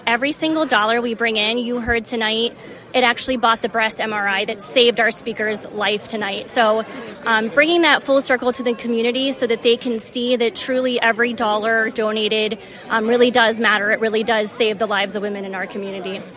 The IRMC Healthcare Foundation kicked off the 20th annual Love of Life fundraising campaign, benefitting the Women’s Imaging Center, Thursday night.